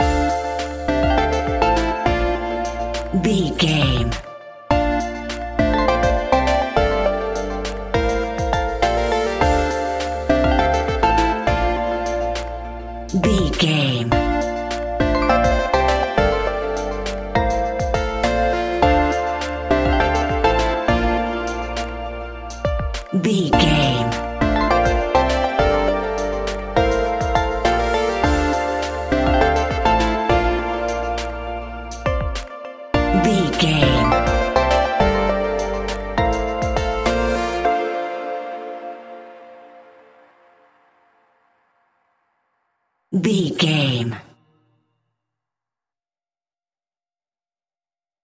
Aeolian/Minor
D
hip hop
instrumentals
chilled
laid back
hip hop drums
hip hop synths
piano
hip hop pads